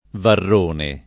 [ varr 1 ne ]